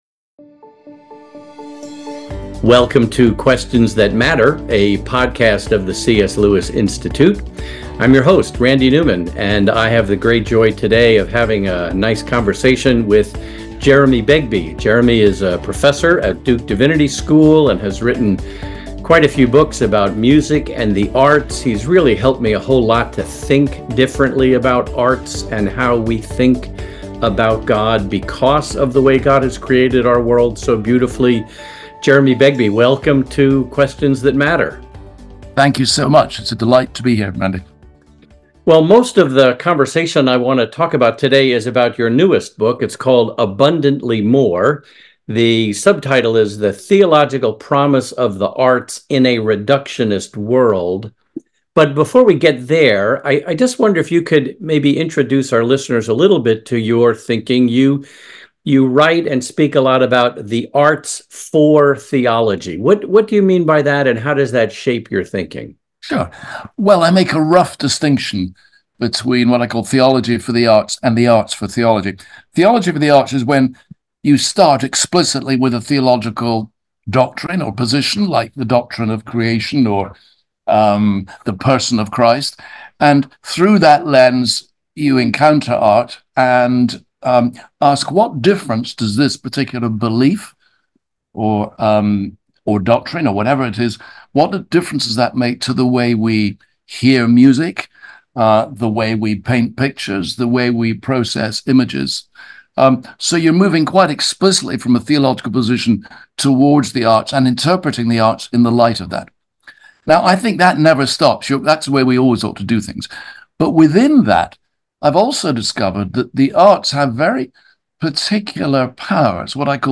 Join us every two weeks for a fascinating conversation with one of today’s Christian faith leaders.